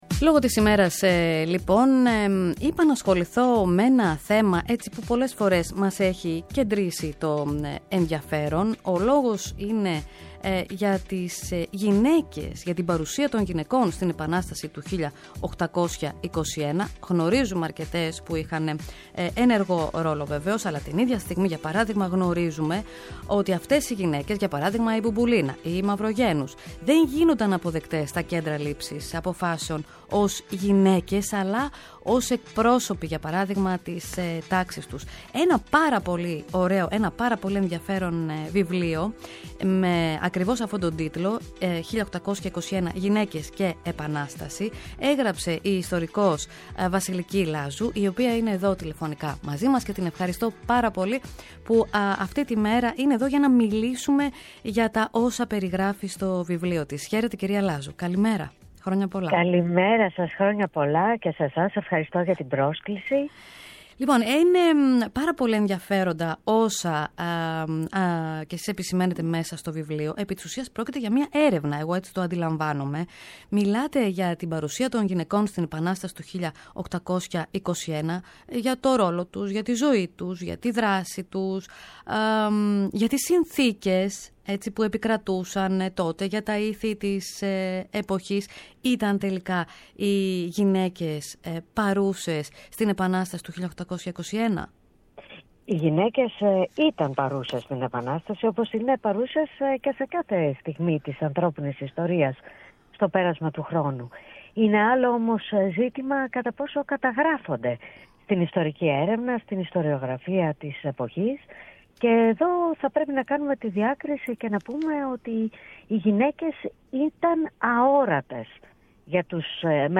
Αυτά είναι κάποια από τα ερωτήματα που έγιναν αφορμή για μια συζήτηση